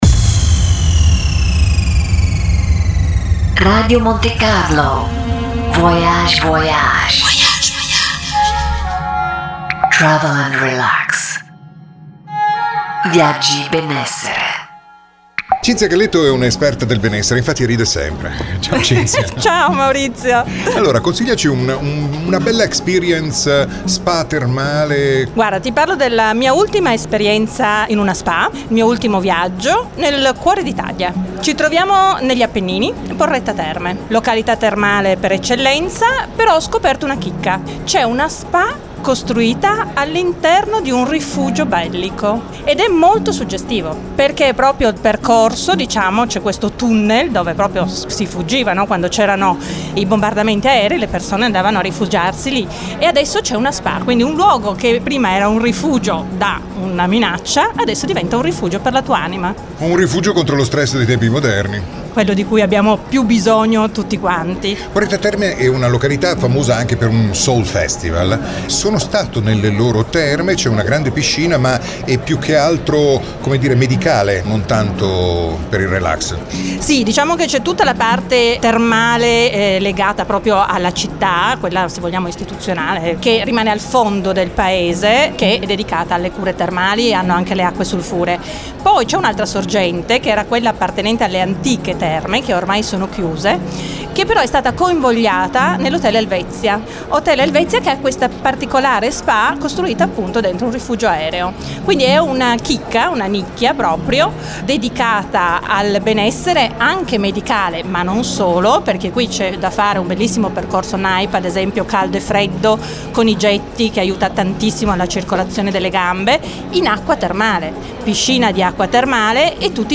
Fra le curve morbide e sinuose dell’Appennino tosco-emiliano si nasconde una sorgente di benessere… un “rifugio” per ritrovare nuovi spazi, tempi e stili di vita: è la spa termale dell’Hotel Helvetia di Porretta Terme. Anche in questa mia intervista